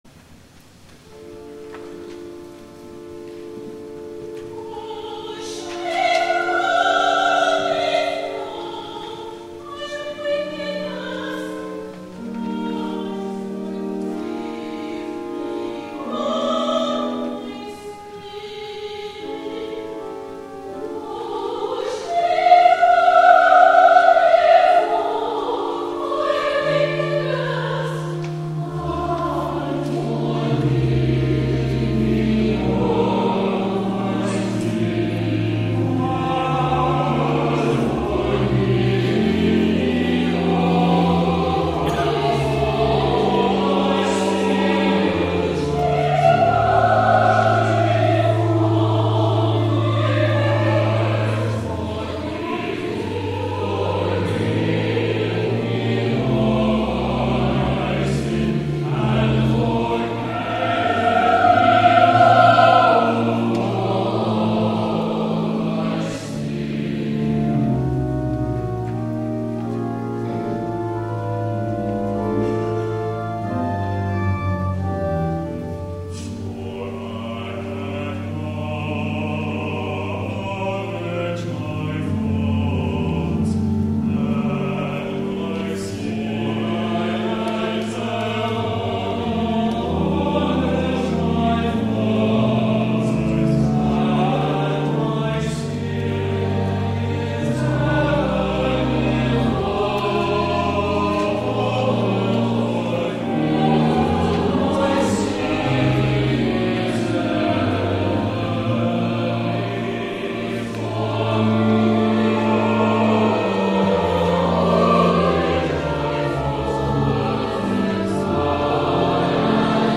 THE ANTHEM